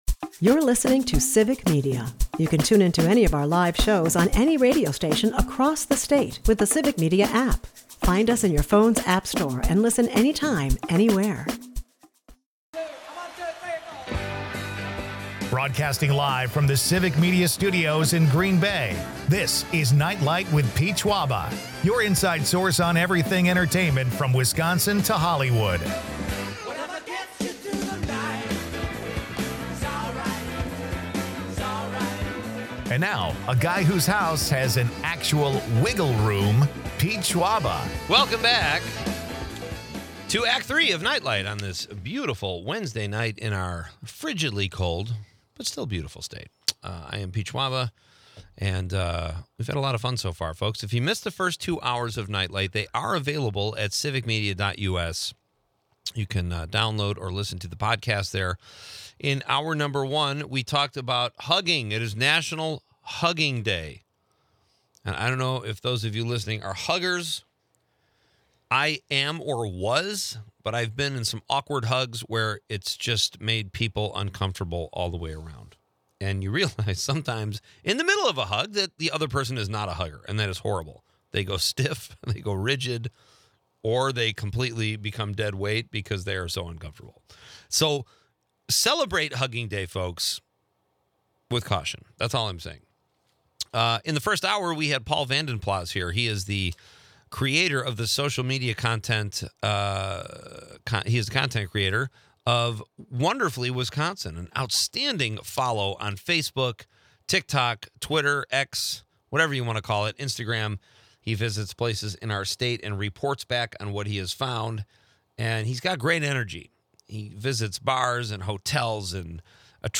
Broadcasting from chilly Wisconsin
Finally, the night wraps with a rousing debate on Kevin Bacon's best films, leaving no cinematic stone unturned.